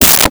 Space Gun 16
Space Gun 16.wav